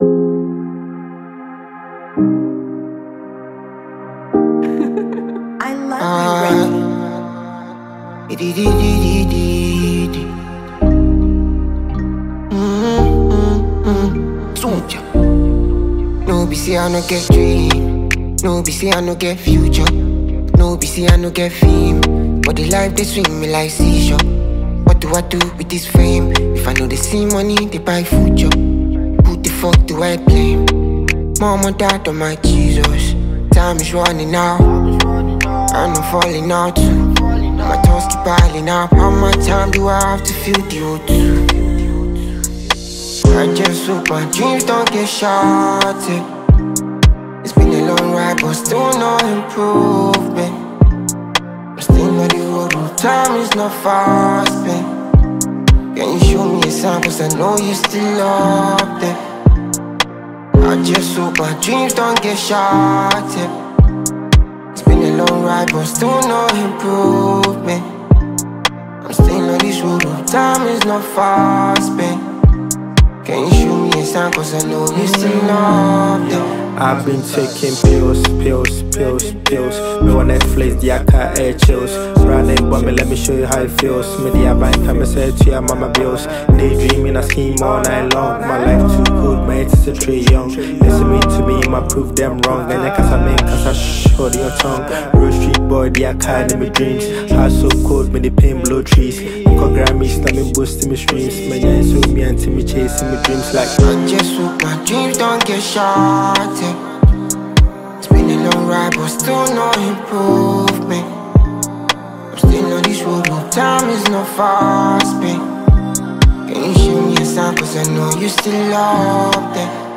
a motivational drill anthem.